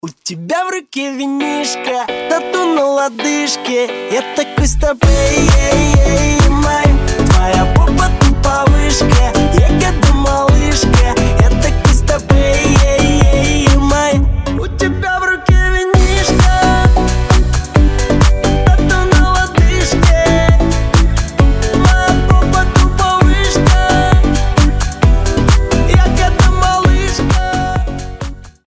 • Качество: 320, Stereo
зажигательные
веселые